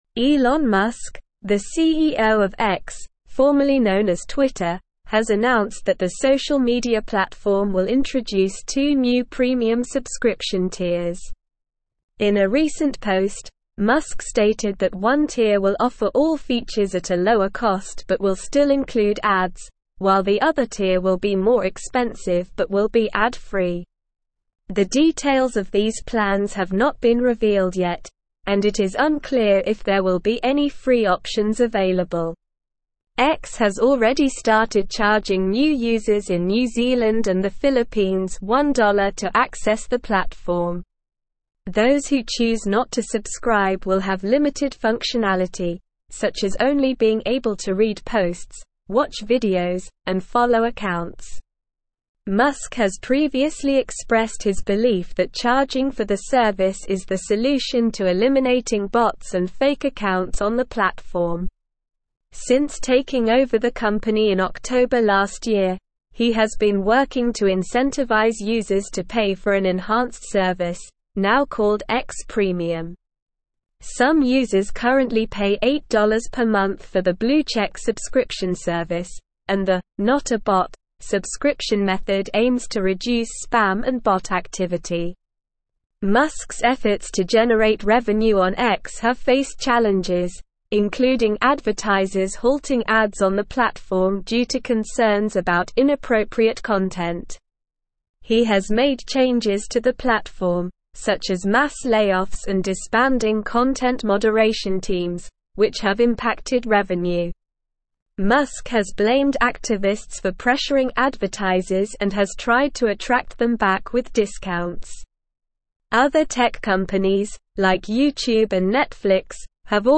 Slow
English-Newsroom-Advanced-SLOW-Reading-Elon-Musks-X-to-Introduce-Premium-Subscriptions.mp3